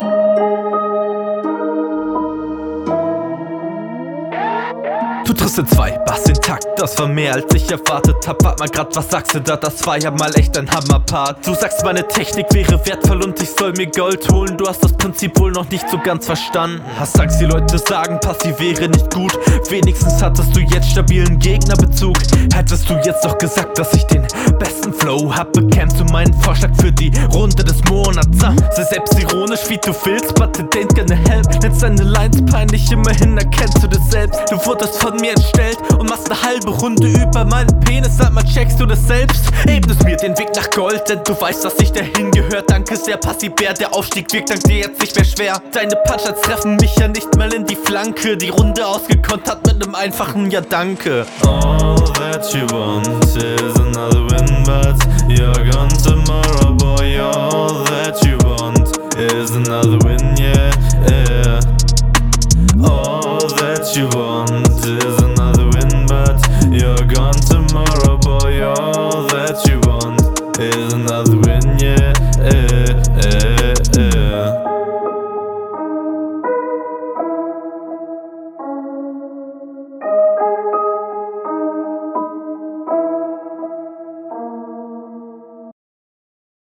Bessere Soundqualität, ähnlich gut gerappt, bei der Technik wurde was versucht, aber es sind keine …
Stark geflowt und überwiegend gute Konter.
Flow wieder ziemlich stabil, teilweise starke Betonungen.